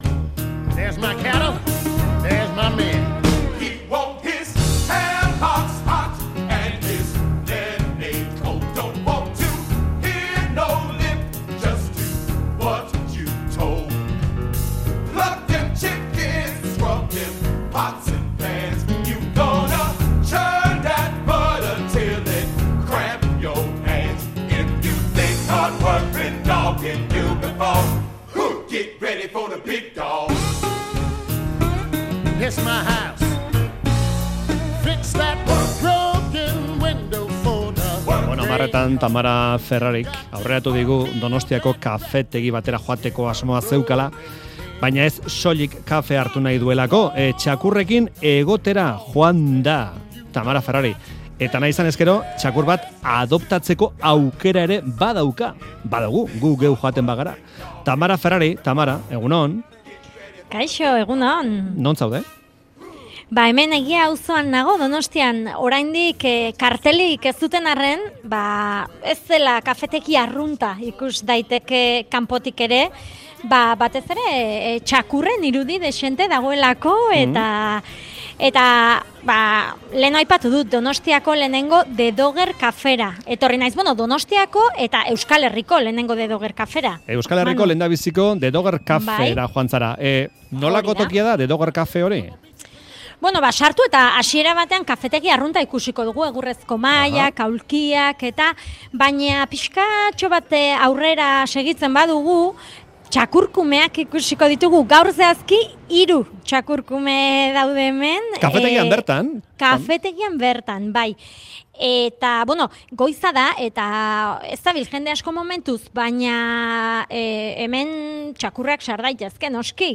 Audioa: Euskal Herriko lehenengo The Doger Cafean izan da 'Faktoria'.